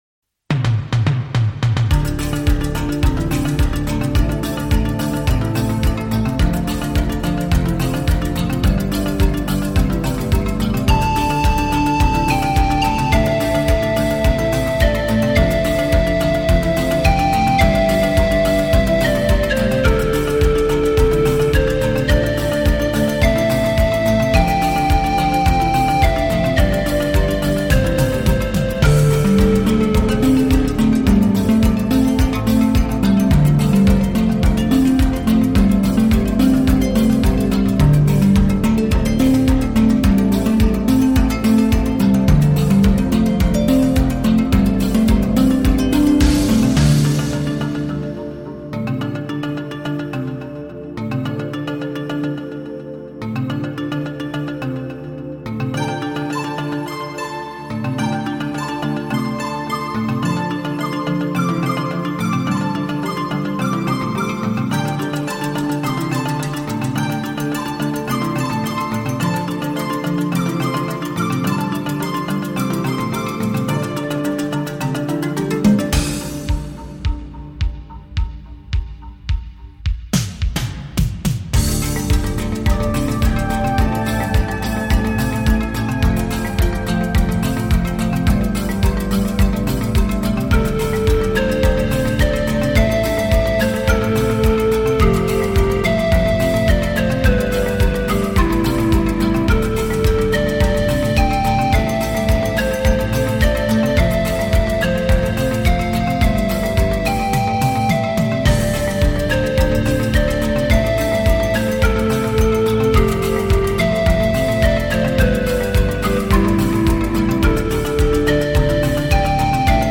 musique synthé